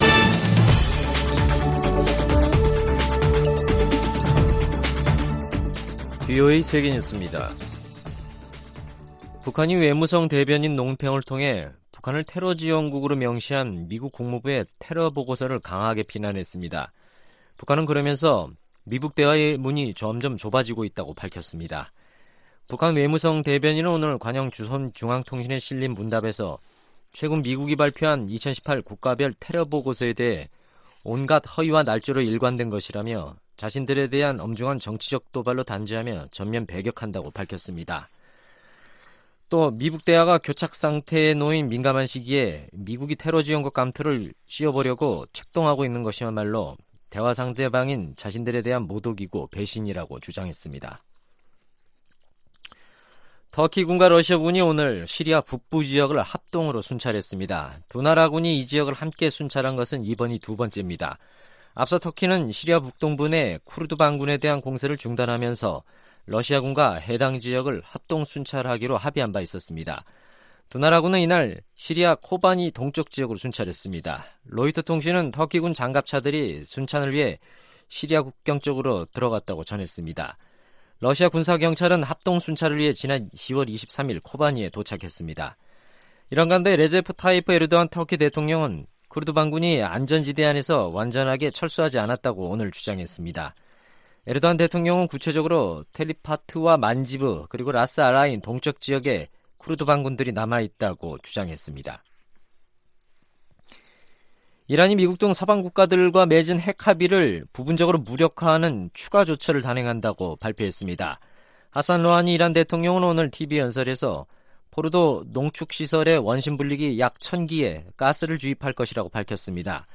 VOA 한국어 간판 뉴스 프로그램 '뉴스 투데이', 2019년 11월 5일 2부 방송입니다. 미-북 협상에 진전이 없는 가운데, 미국 의회 일각에서는 북한 문제에 대한 ‘다음 단계’를 논의해야 한다는 목소리가 나오고 있습니다. 유엔총회는 북한의 완전한 비핵화를 촉구하는 3건의 결의안을 채택했습니다..